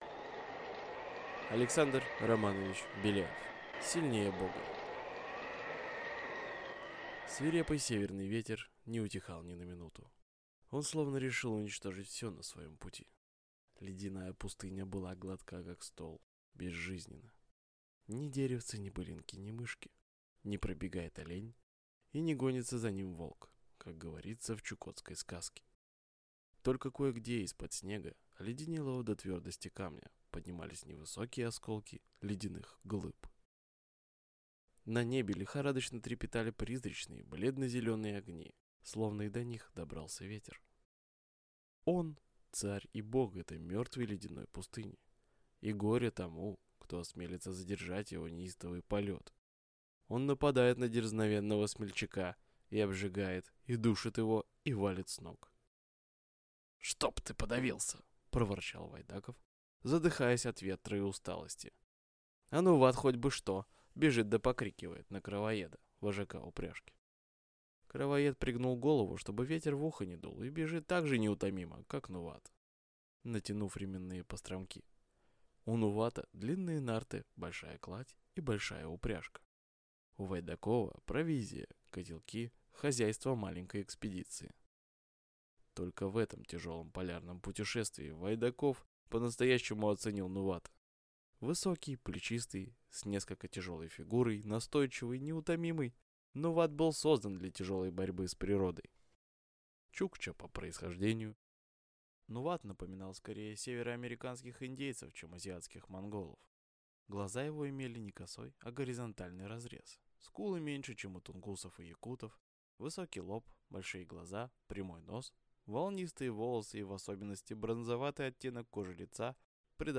Аудиокнига Сильнее бога | Библиотека аудиокниг